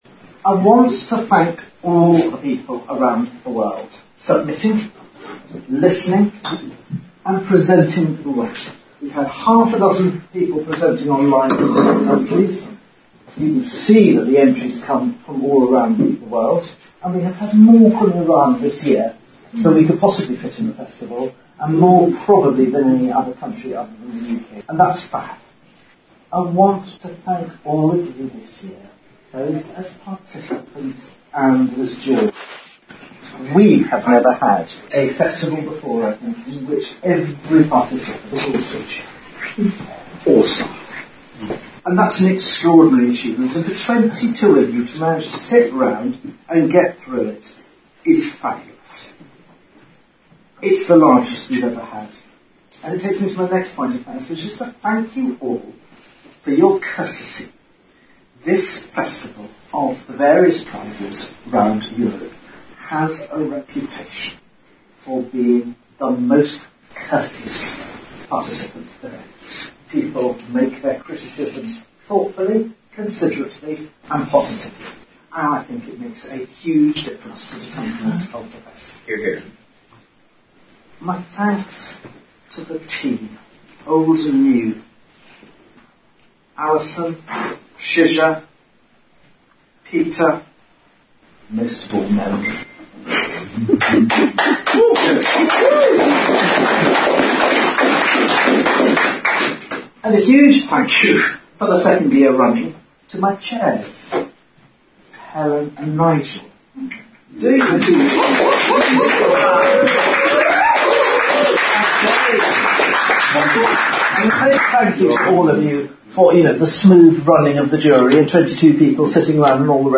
The presentations were recorded, and the soundtrack of the ceremony is